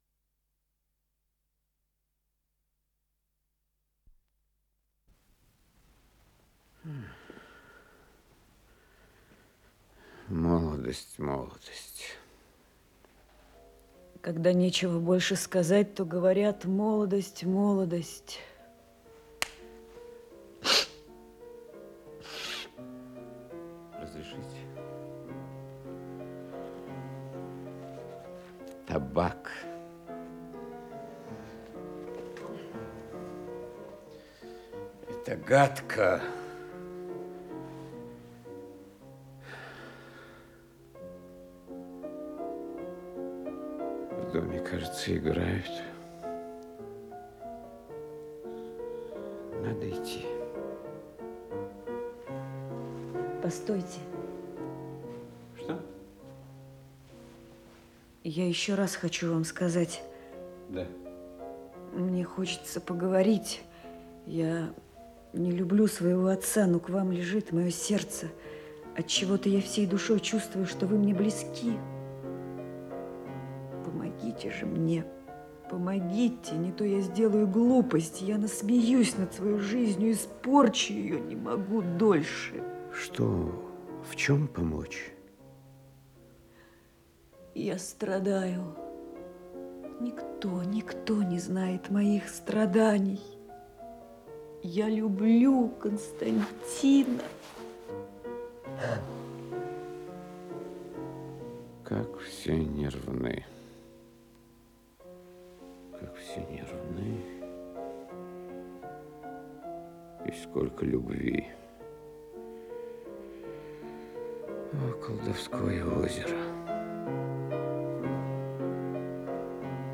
Исполнитель: Артисты МХАТ СССР им. Горького
Спектакль